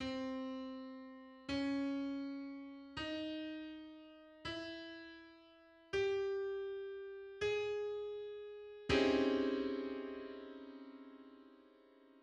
6-Z19: "Schoenberg hexachord" complement in prime form
Its Z-related hexachord and complement is 6-Z19[1] (3478te or, in prime form, 013478).